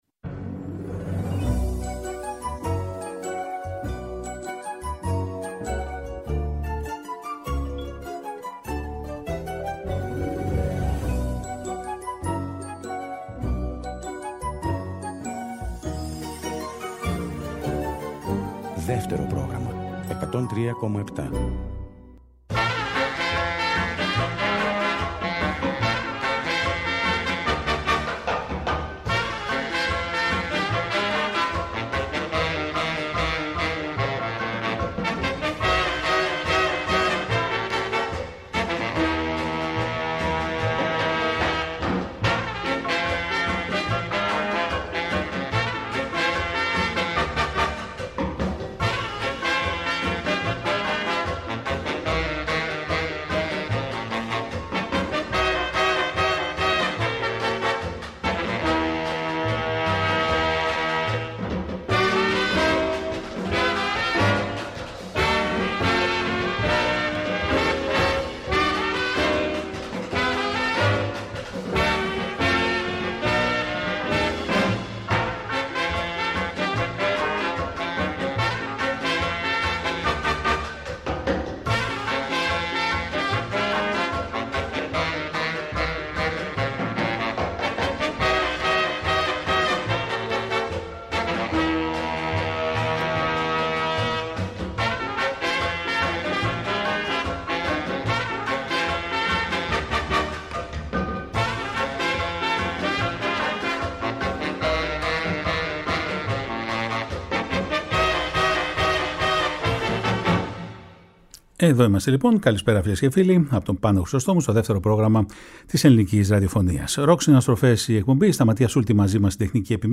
Με αφορμή στην πρόσφατη γνωστή επιτυχημένη τηλεοπτική σειρά, έρχεται αφιέρωμα για την ελληνική ποπ μουσική στα χρόνια 1962-1972.
Δευτέρα 25 Ιανουαρίου το πρώτο επεισόδιο με τις διασκευές.